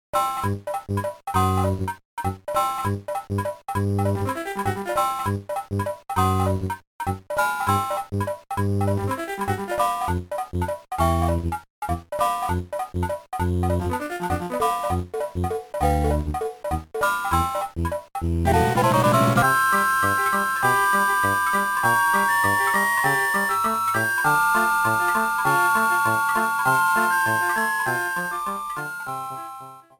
The title screen music
Trimmed and fadeout